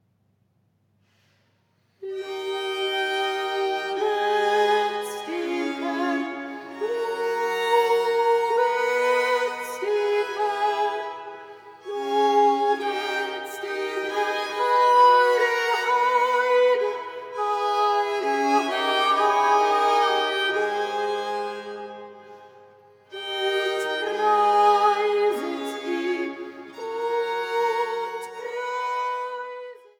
Kleindiskantgeige
Diskantgeige
Tenorgeige
Bassgeigen
Sopran